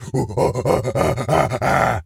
gorilla_chatter_10.wav